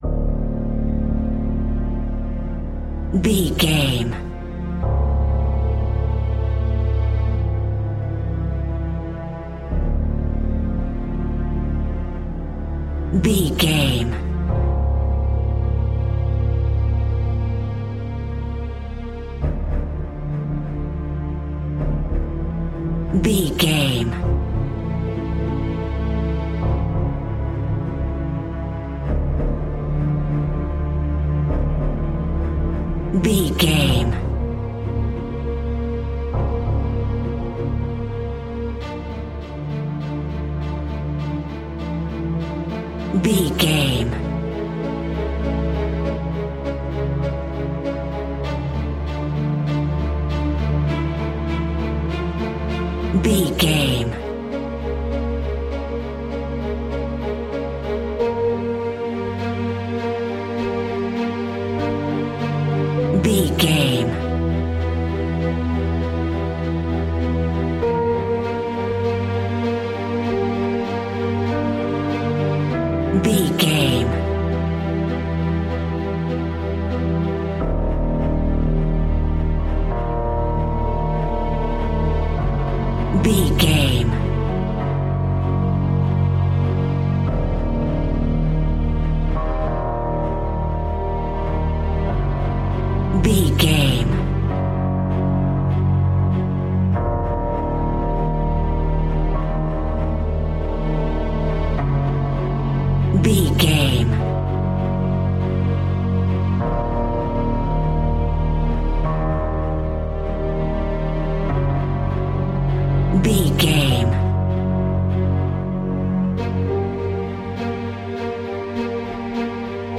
Scary Modern Film Music.
Aeolian/Minor
tension
ominous
dark
haunting
eerie
strings
piano
violin
cello
double bass
Horror Pads
Horror Synths